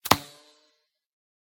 whine_12.ogg